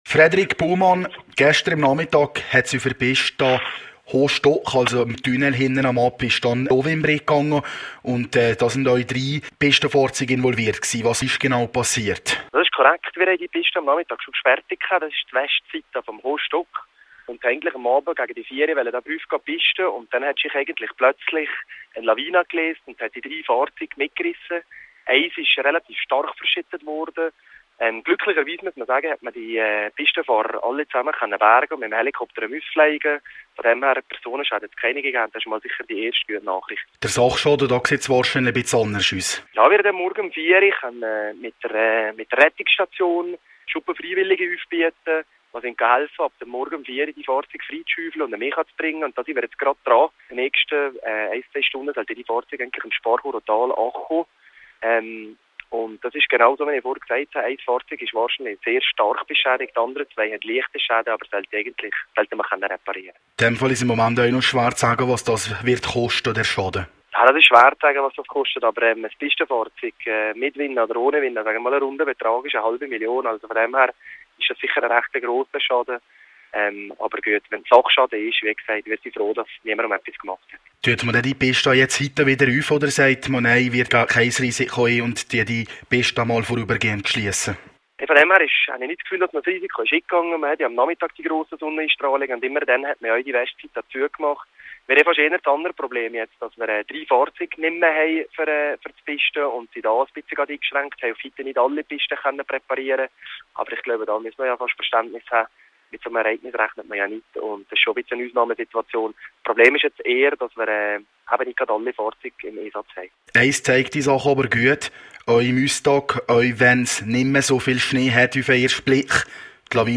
jt Interview zum Thema